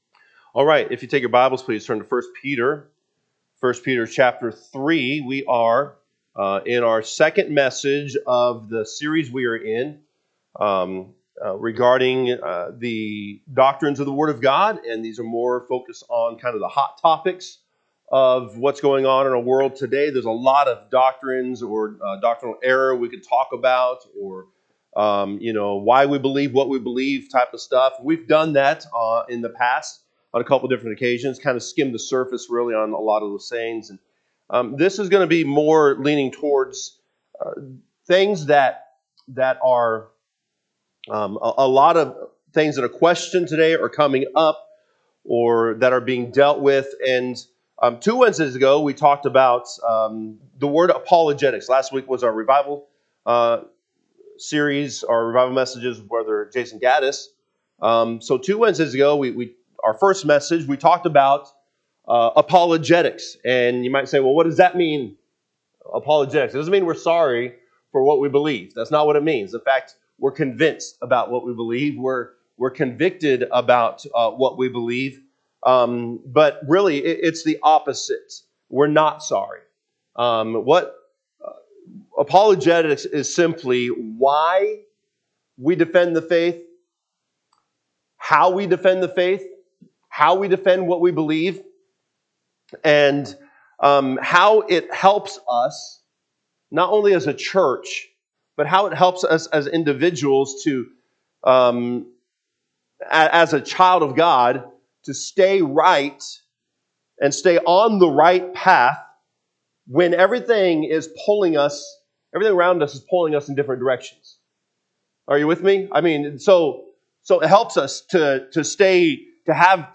- NORTHWEST BAPTIST CHURCH